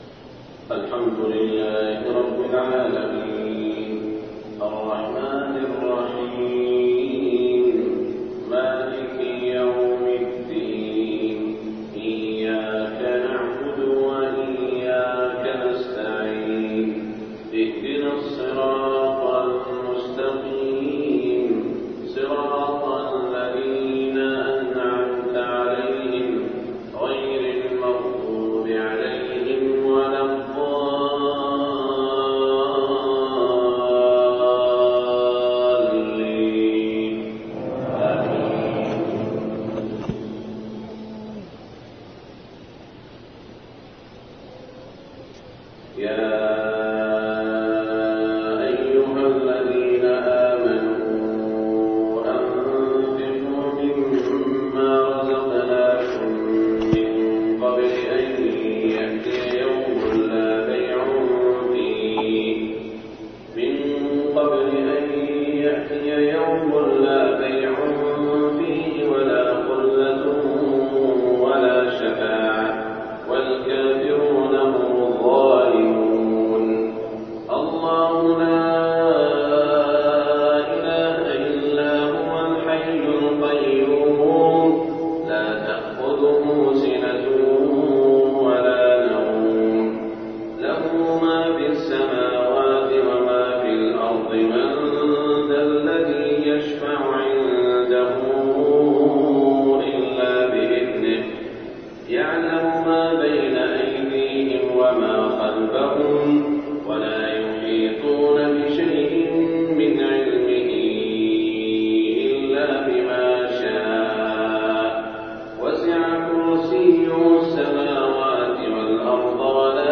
صلاة المغرب 1427 من سورة البقرة > 1427 🕋 > الفروض - تلاوات الحرمين